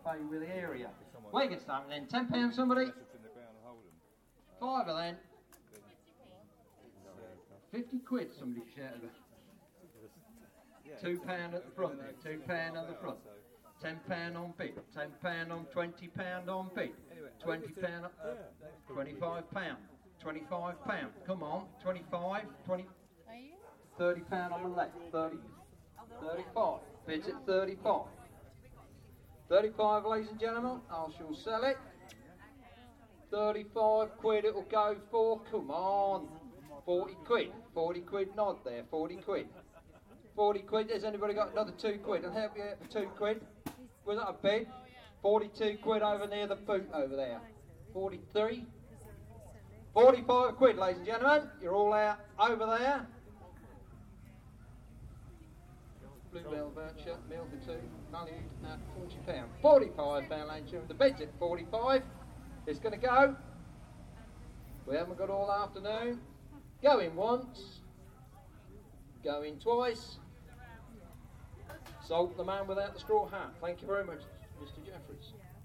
Auctioning
Auctioning prizes at the village fete.